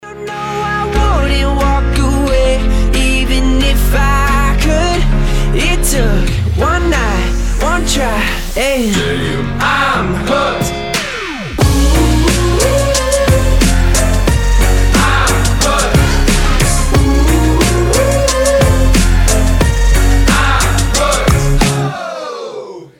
• Качество: 320, Stereo
поп
громкие
зажигательные
заводные
веселый мотив